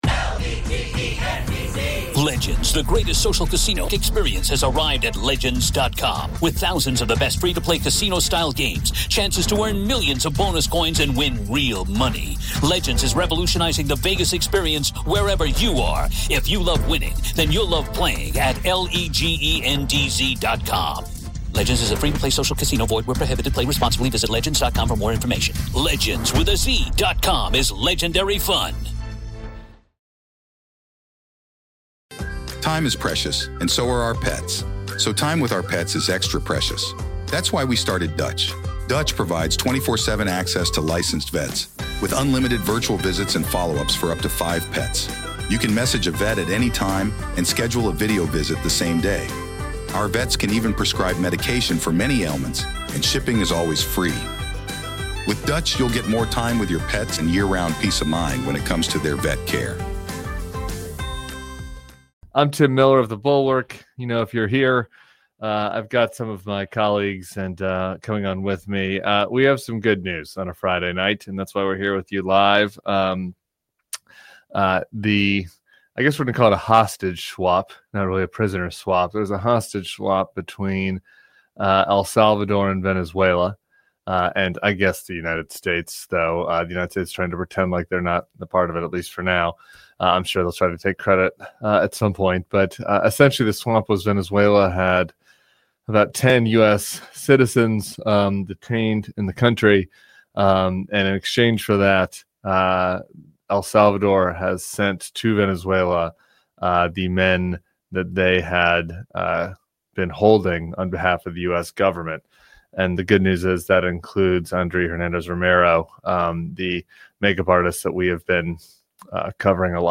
go live to discuss breaking news